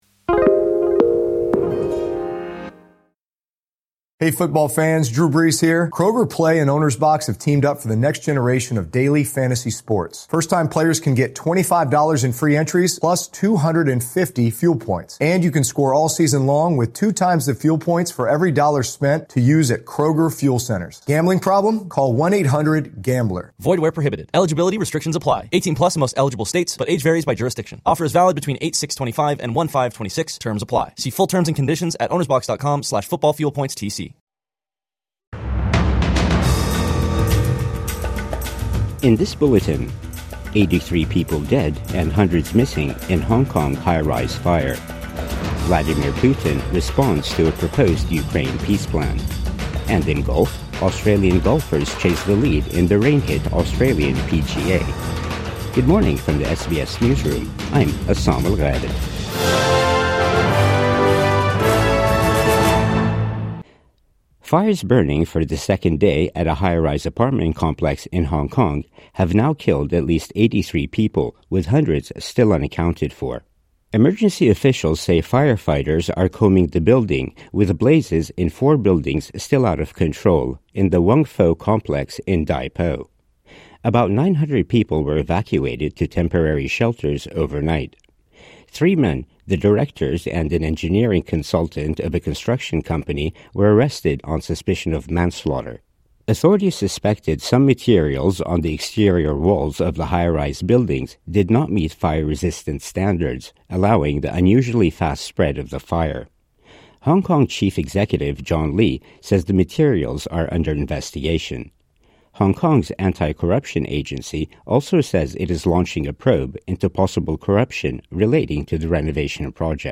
Scores dead, hundreds missing in Hong Kong high-rise blaze | Morning News Bulletin 28 November 2025